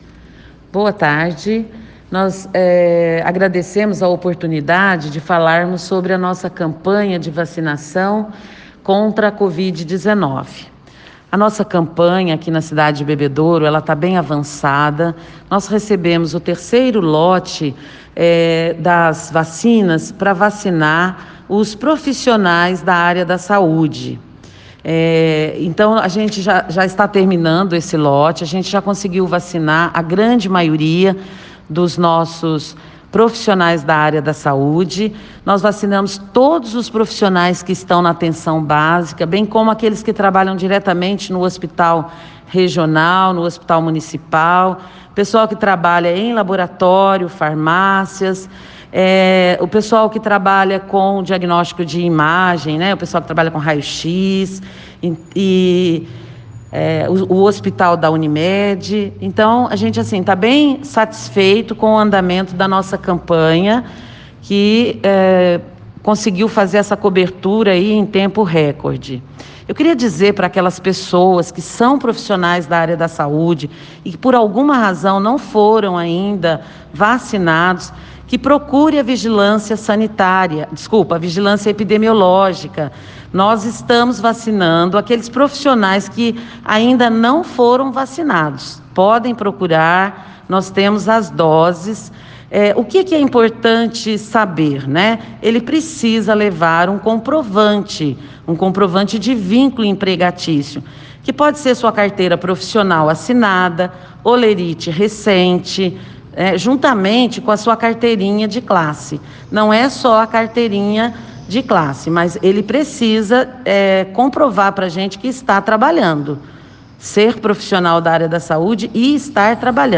A secretária de Saúde, Silvéria Maria Peixoto Laredo, explica como está a vacinação contra o novo coronavírus em Bebedouro, convida profissionais da saúde que perderam a data a procurar a Vigilância Epidemiológica e dá detalhes como será a vacinação dos idosos a partir desta segunda-feira (08).